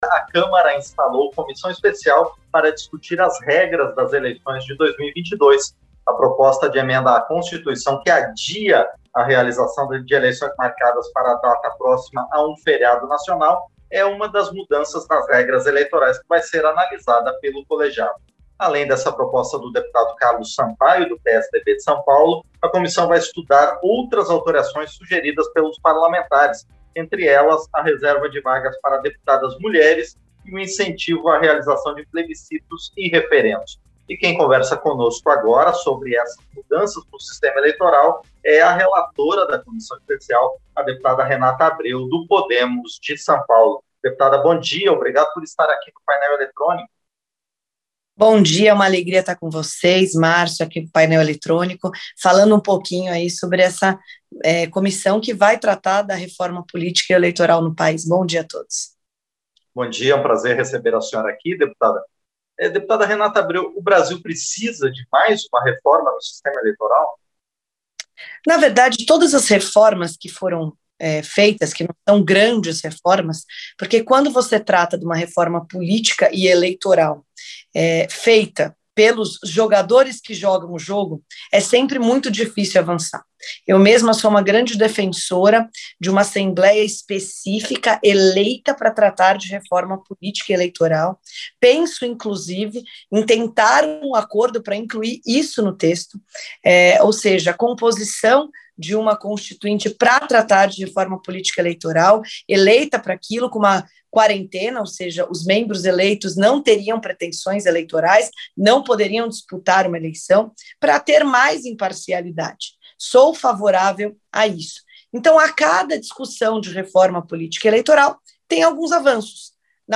Entrevista - Dep. Renata Abreu (Pode-SP)